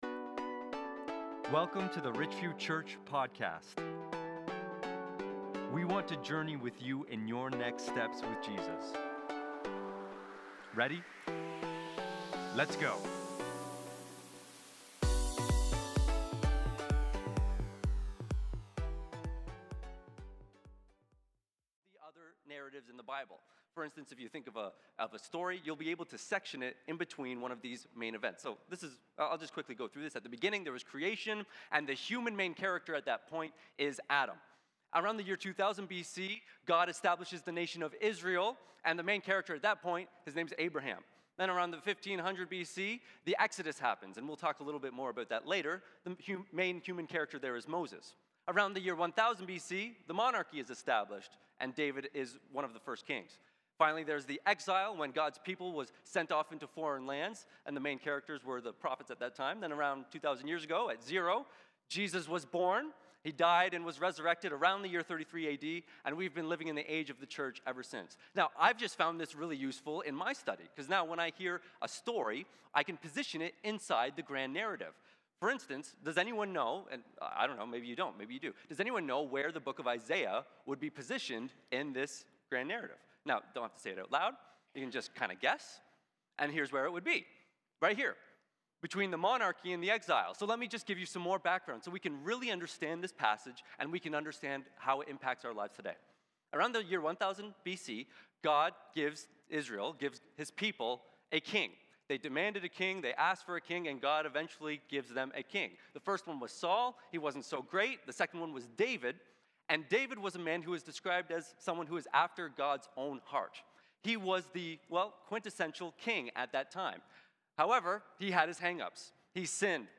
Isaiah 43:15-25 **Note - Sermon is missing the first sentences